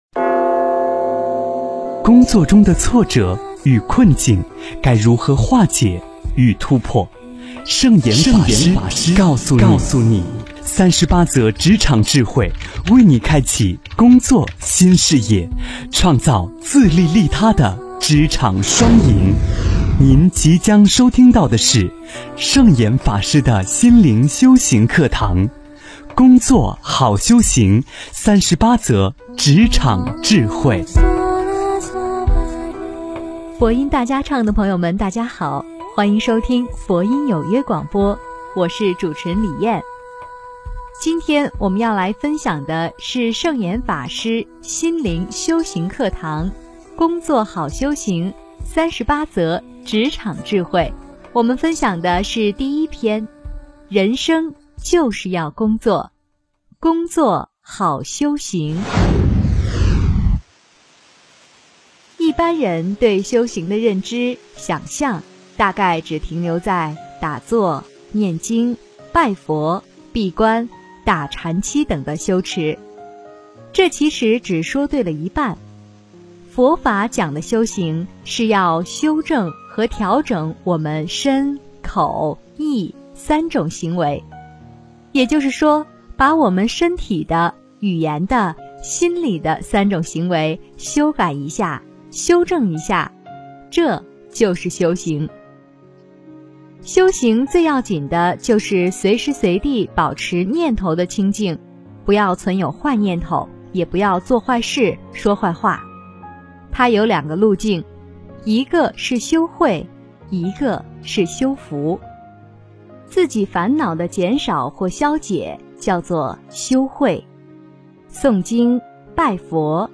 职场05工作好修行--佛音大家唱 真言 职场05工作好修行--佛音大家唱 点我： 标签: 佛音 真言 佛教音乐 返回列表 上一篇： 职场03为磨练自己而工作--佛音大家唱 下一篇： 职场08如何面对中年失业--佛音大家唱 相关文章 伽蓝圣众菩萨--萧煌奇&孟庭苇 伽蓝圣众菩萨--萧煌奇&孟庭苇...